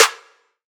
DDW Snare 5.wav